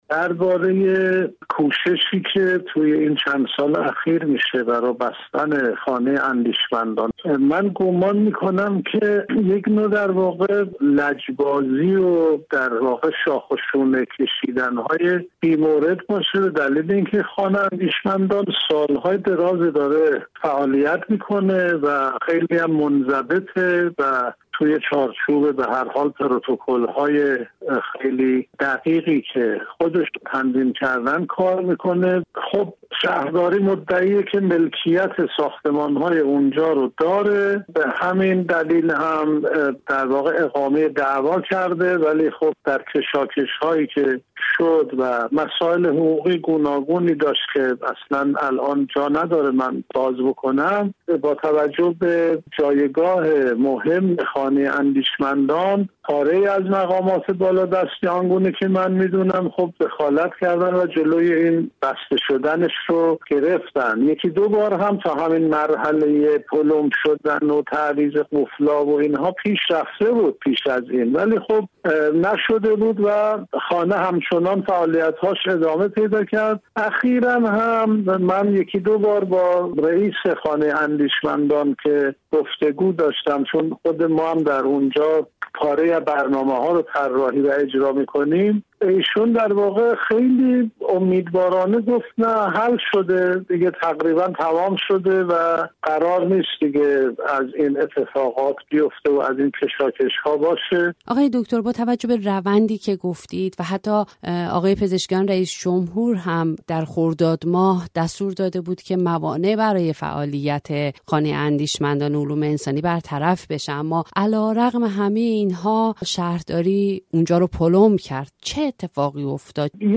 پلمپ خانهٔ اندیشمندان علوم انسانی در گفت‌وگو